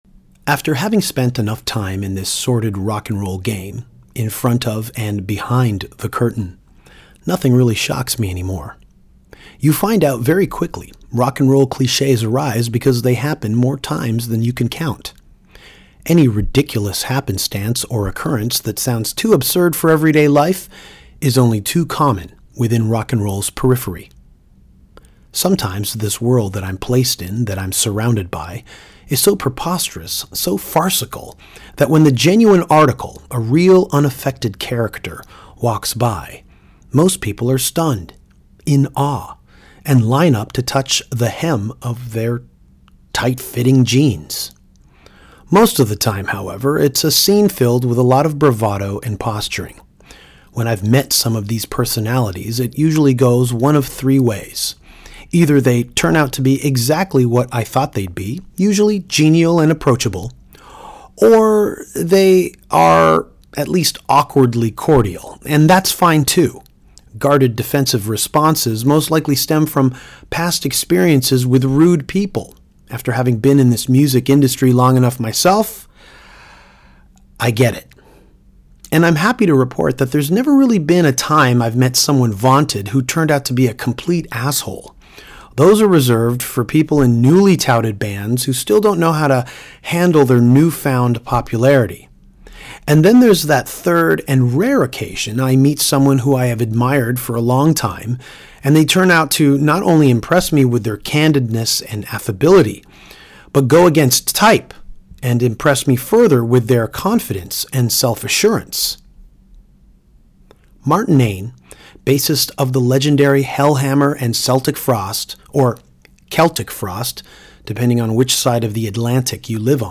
Danko spoke to Martin Ain (Celtic Frost/Hellhammer) backstage at The Greenfield Festival in Interlacken, Switzerland this past June.